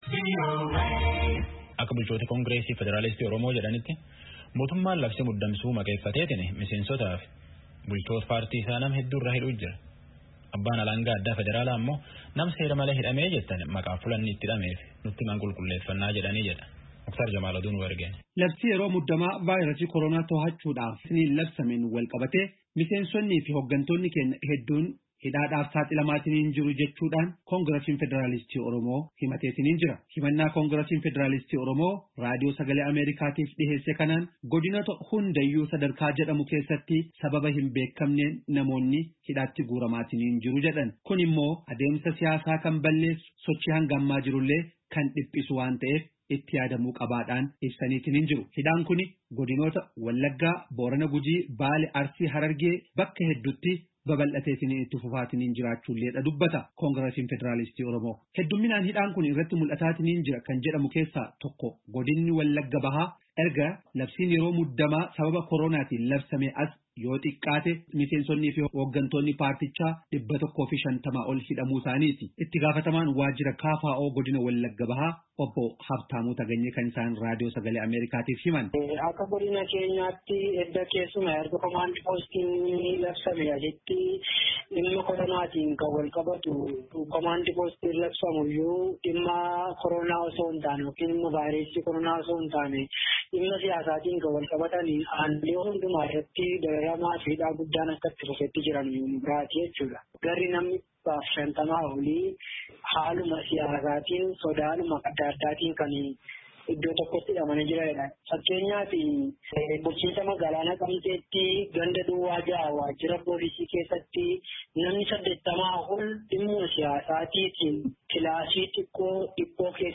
Gabaaasaa guutuu caqasaa